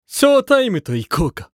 男性
厨二病ボイス～戦闘ボイス～
【戦闘開始1】